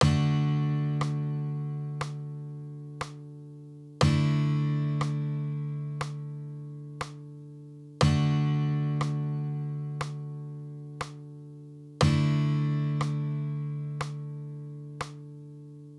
The audio samples that follow each pair will sound out the traditional chord first followed by the easy version.
D and D easy chords
dmaj-easy.wav